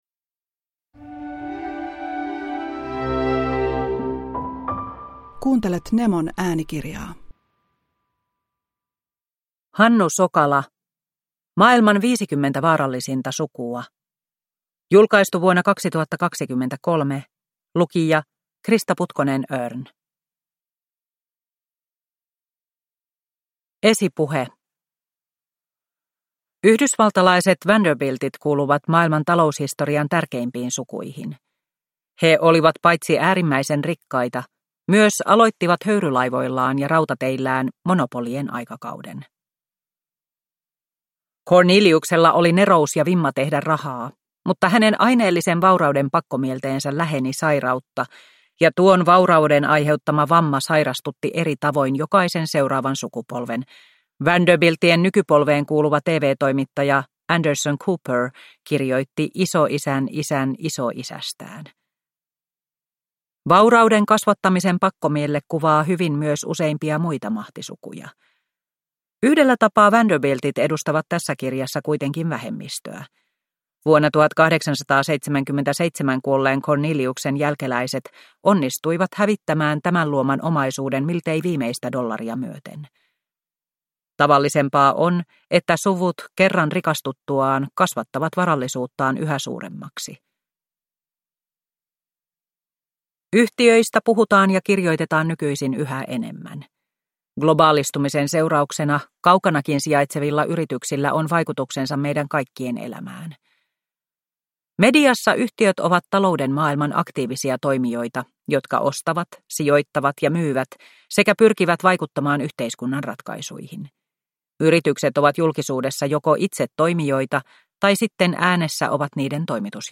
Maailman 50 vaarallisinta sukua – Ljudbok – Laddas ner